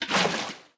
Minecraft Version Minecraft Version 25w18a Latest Release | Latest Snapshot 25w18a / assets / minecraft / sounds / item / bucket / empty2.ogg Compare With Compare With Latest Release | Latest Snapshot